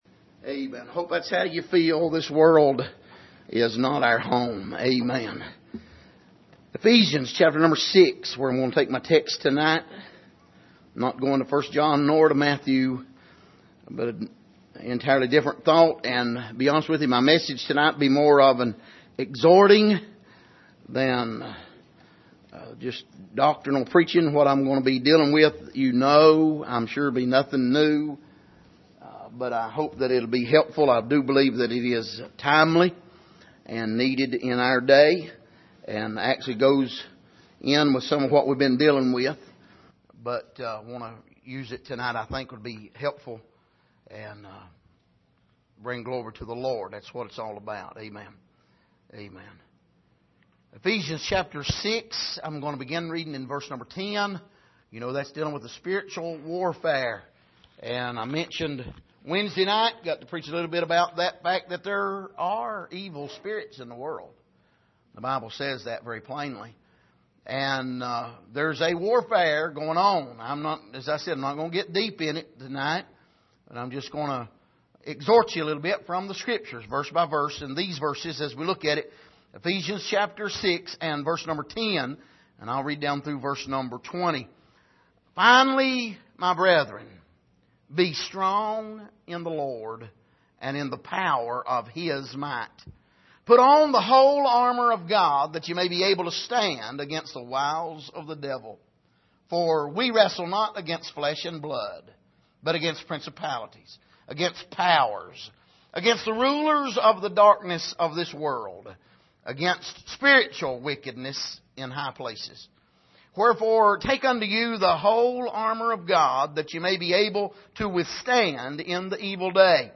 Passage: Ephesians 6:10-20 Service: Sunday Evening